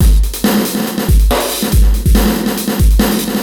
E Kit 15.wav